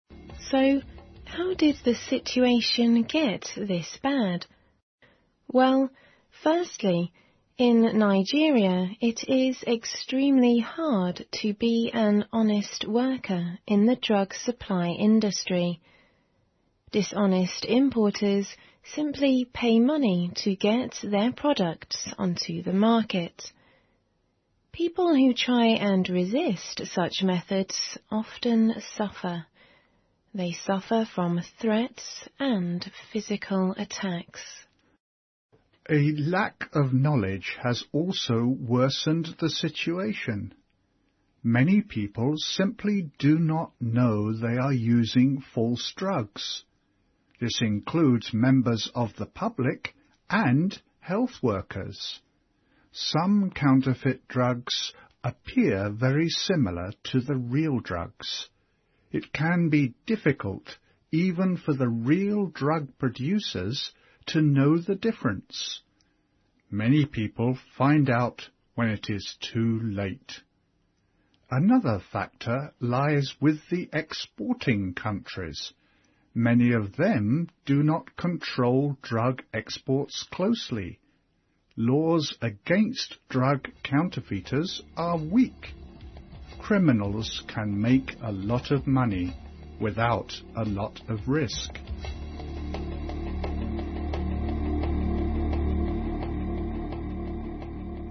BBC环球慢速英语 第90期:朵拉医生(4)MP3音频下载,《BBC环球慢速英语》，从慢速英语入手是真正科学的听力突破方法，使你的英语听力突飞猛进。